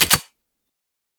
select-pistol-3.ogg